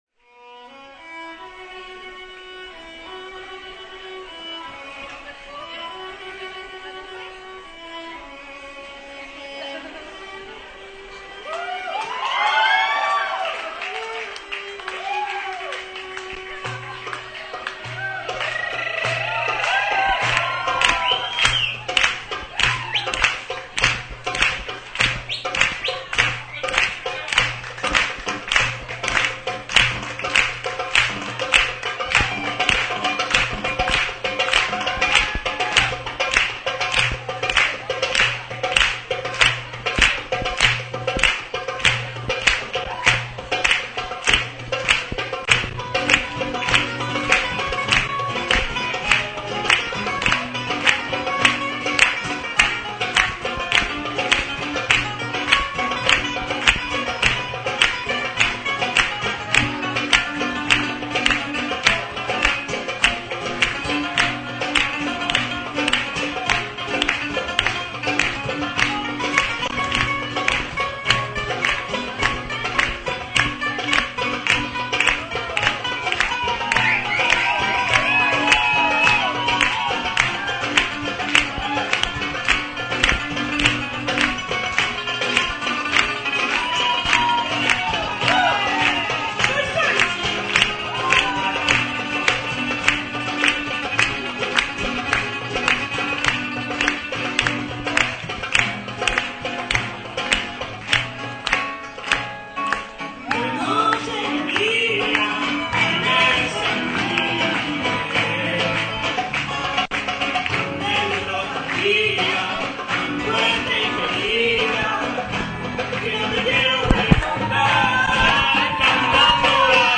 ! Extraits du concert au format mp3 !